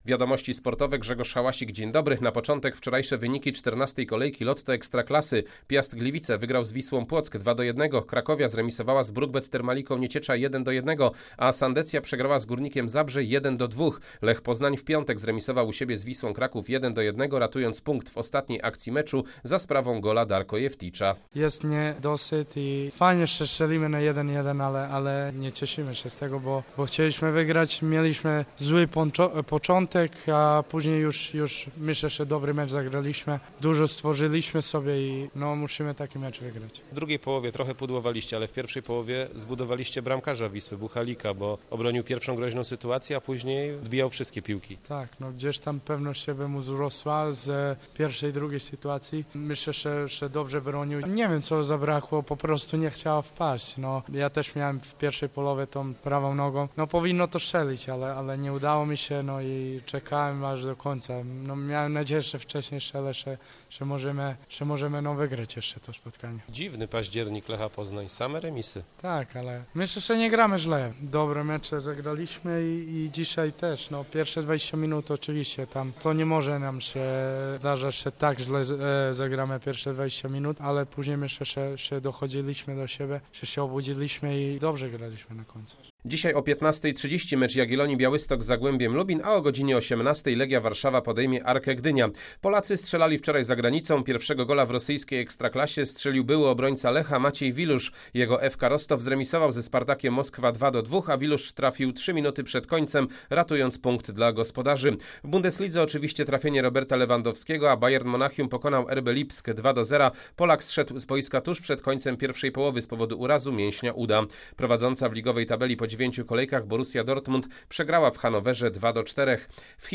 29.10 Serwis sportowy g. 09.15